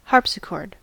Ääntäminen
US : IPA : /ˈhɑɹp.sɪˌkɔɹd/